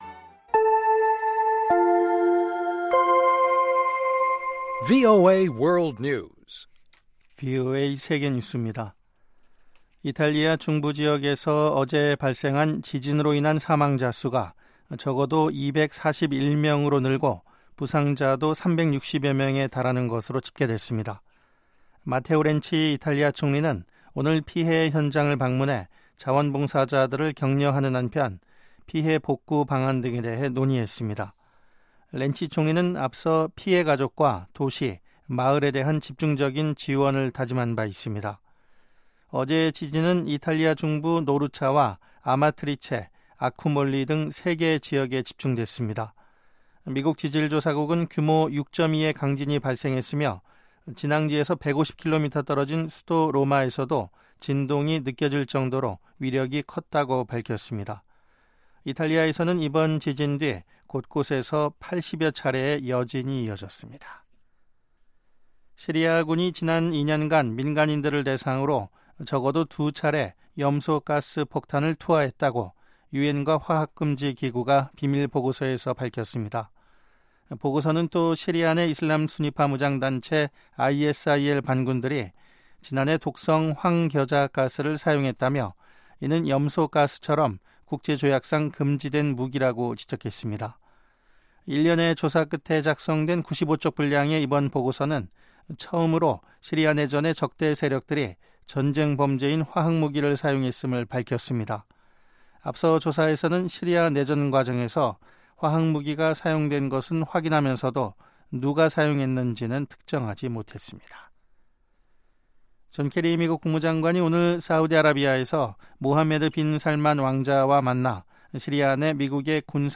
VOA 한국어 방송의 간판 뉴스 프로그램 '뉴스 투데이' 2부입니다.